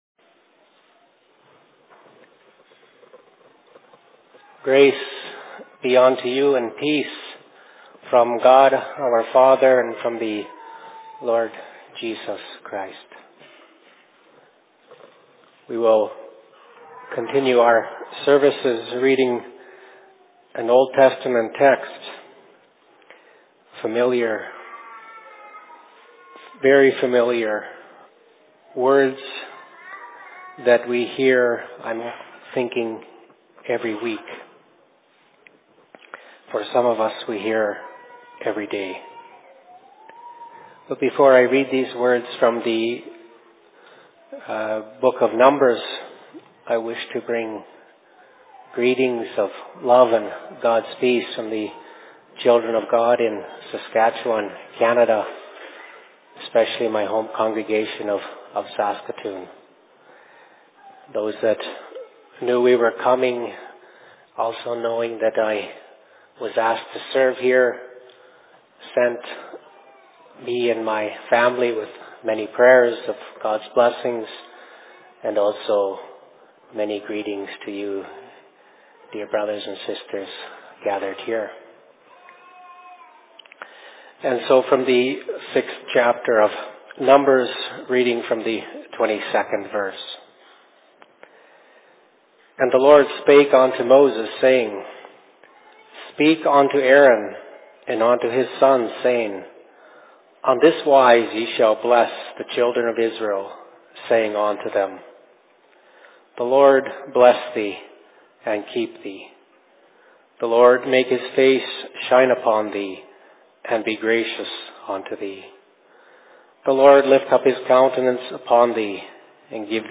Sermon in LLC Winter Services 2017, Phoenix 24.02.2017
Location: LLC 2017 Winter Services